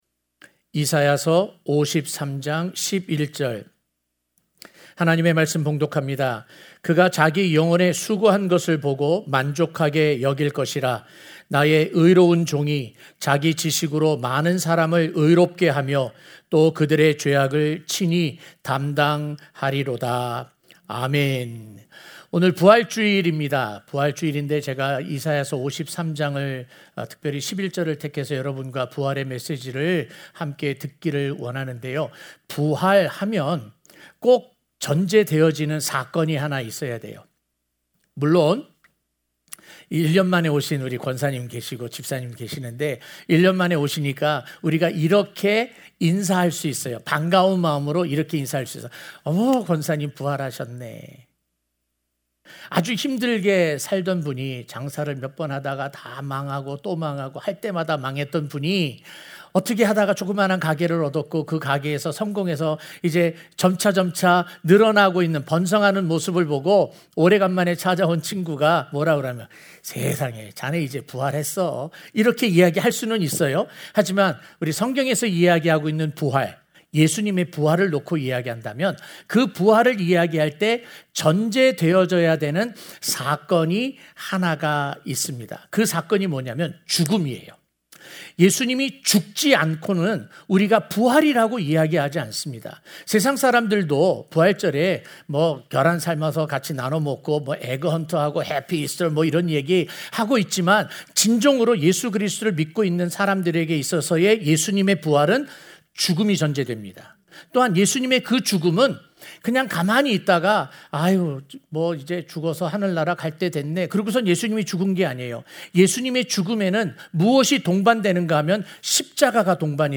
자기 영혼의 수고한 것 (사 53:11)-부활주일설교
주일설교